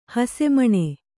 ♪ hase maṇe